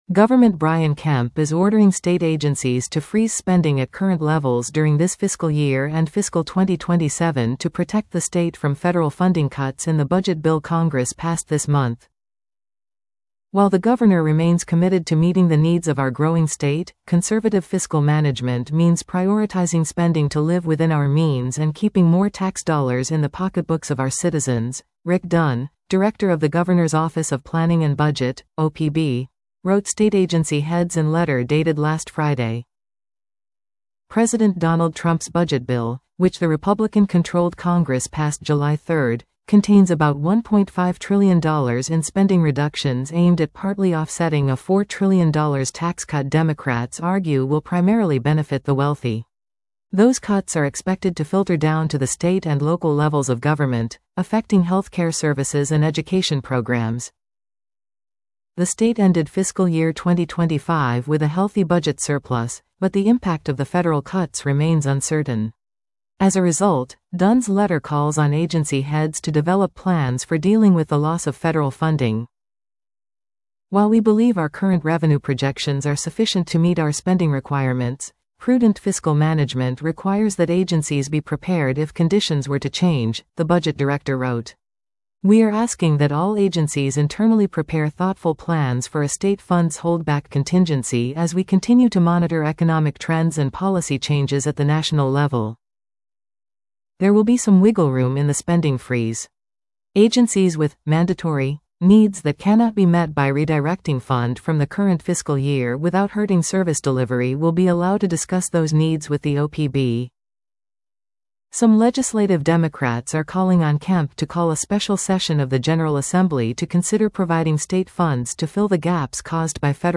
Brian Kemp Capitol Beat News Service Listen to this article 00:02:30 Gov.